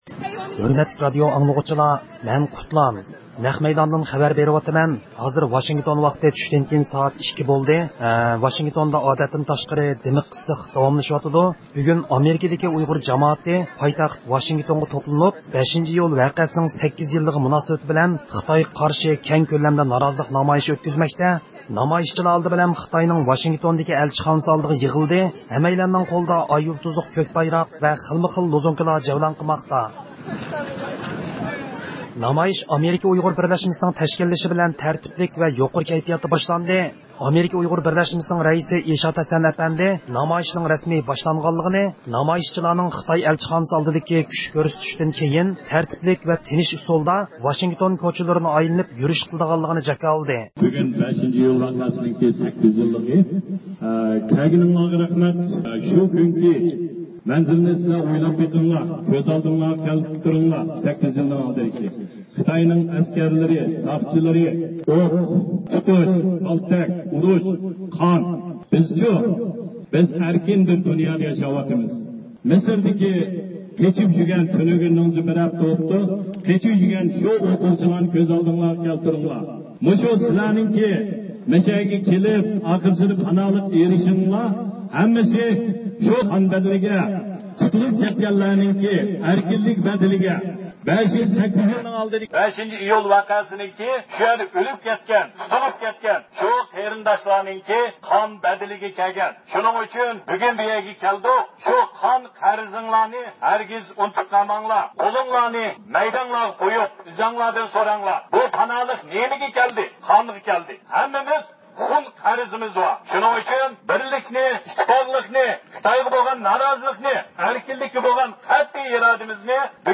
نامايىشچىلار ئالدى بىلەن خىتاينىڭ ۋاشىنگتوندىكى ئەلچىخانىسى ئالدىغا يىغىلىپ، قوللىرىدا ئاي-يۇلتۇزلۇق كۆك بايراق بىلەن خىلمۇ-خىل لوزۇنكىلارنى جەۋلان قىلدۇرغان ھالدا خىتايغا قارشى شوئار توۋلىدى.
دۇنيا ئۇيغۇر قۇرۇلتىيىنىڭ رەئىسى رابىيە قادىر خانىم شۇنچە ئىسسىققا قارىماي بۈگۈنكى نامايىشقا قاتناشتى ۋە شەخسەن ئۆزى يېتەكچىلىك قىلدى.